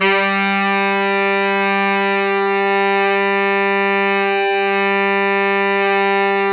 Index of /90_sSampleCDs/Roland LCDP07 Super Sax/SAX_Sax Ensemble/SAX_Sax Sect Ens
SAX SFG3X 0E.wav